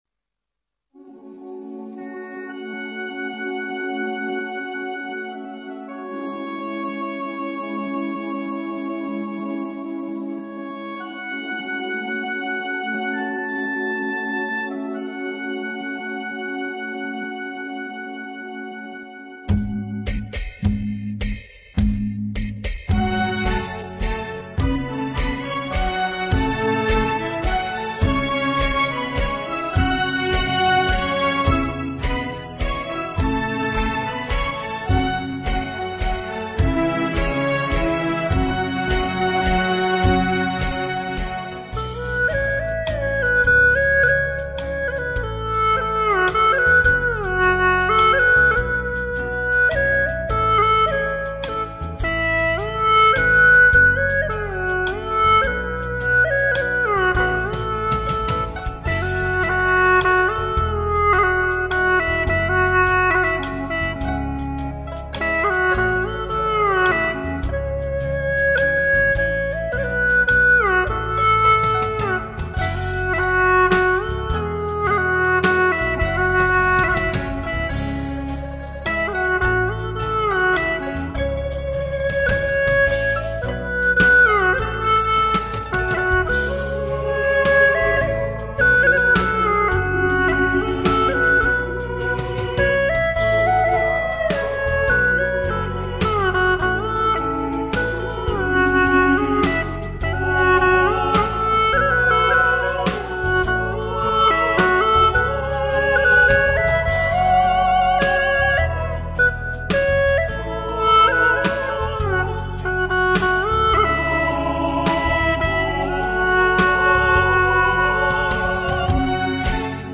曲类 : 独奏
用葫芦丝演奏更是婉转柔美
这首作品大家应该听过很多版本，这一版我是按原调A调演奏的。